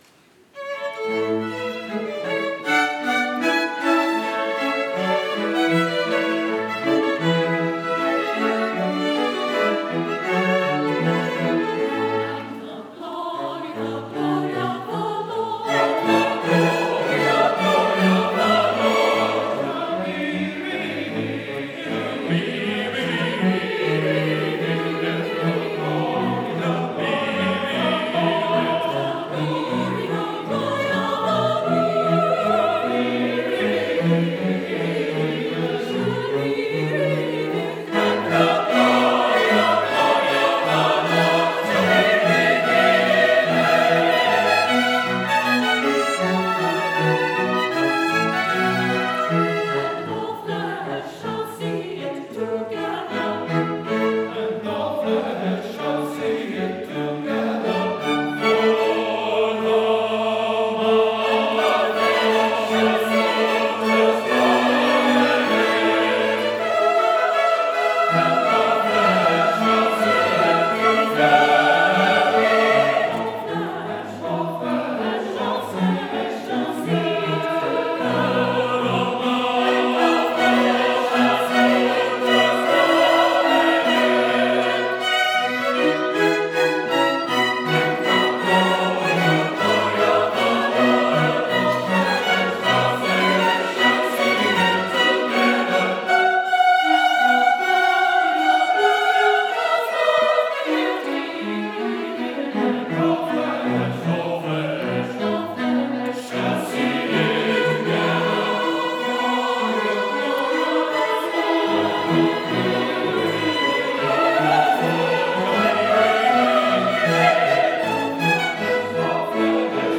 December 13, 2015 Concert